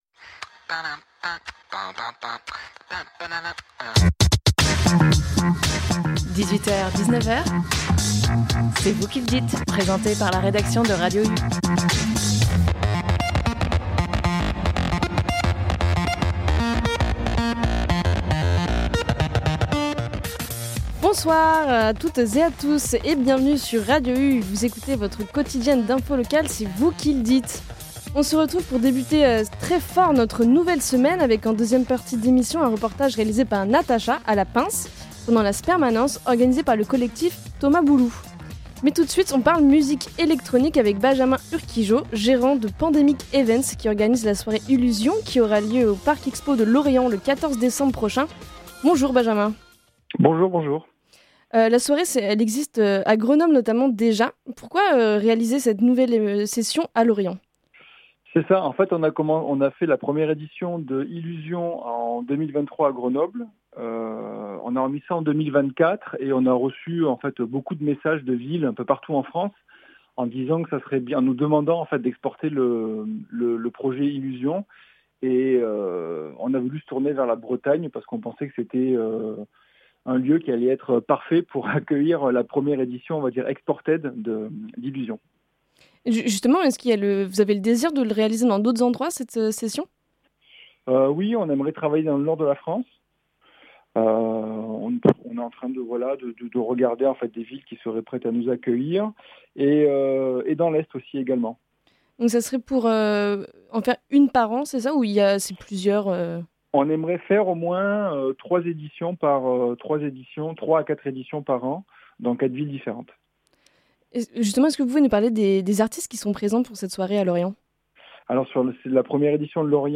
un reportage